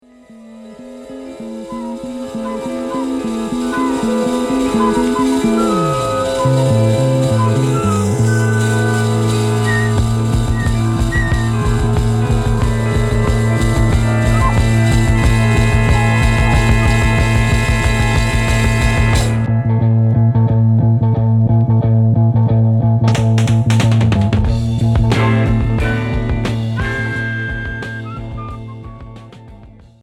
Progressif Deuxième 45t retour à l'accueil